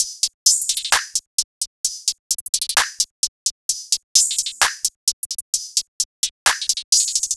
drumloop 5 (130 bpm).wav